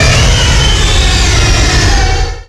Cri de Primo-Kyogre dans Pokémon Rubis Oméga et Saphir Alpha.